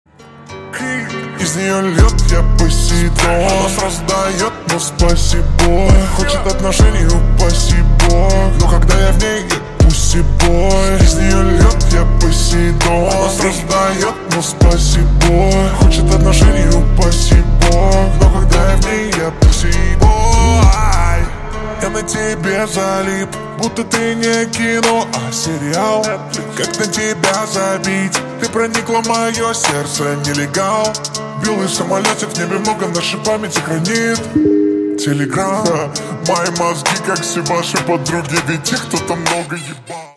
Громкие Рингтоны С Басами
Рингтоны Ремиксы » # Рэп Хип-Хоп Рингтоны